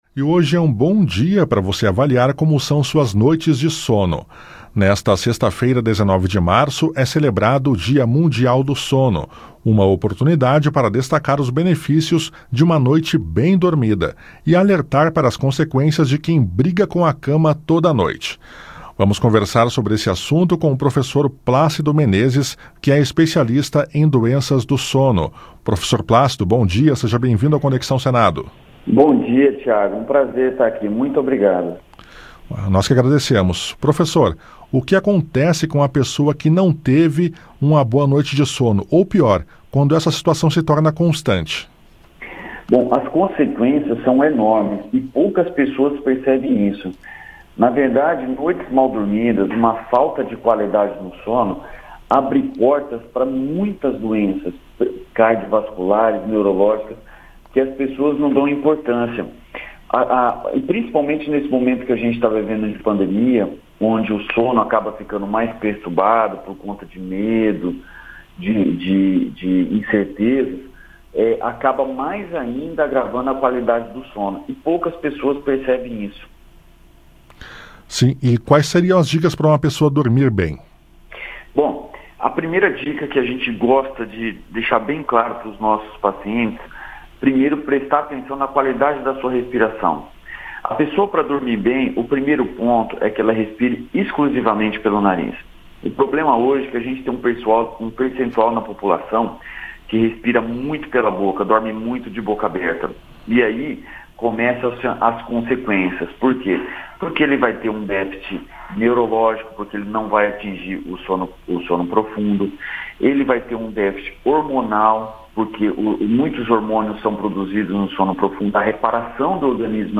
Entrevista: cuidados com o sono durante a pandemia do coronavírus